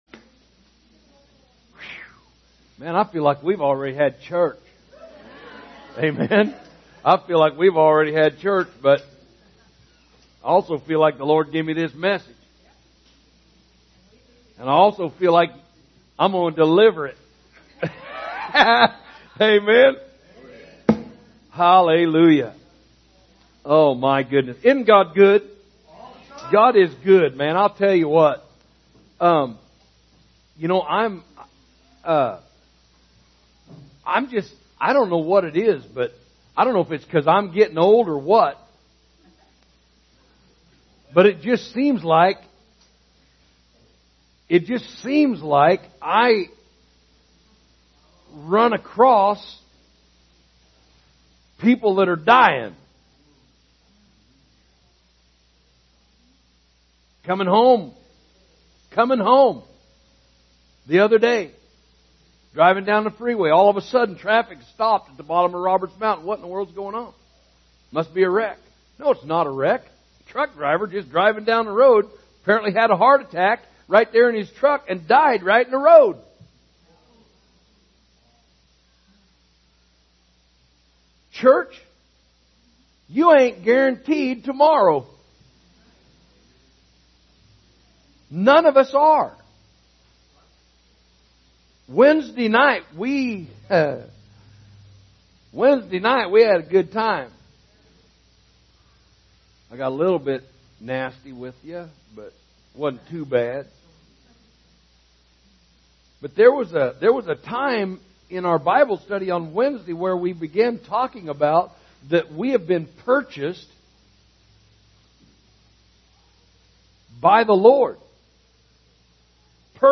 Sermons Archive – Page 28 – Harvest Christian Assembly
Category: Teachings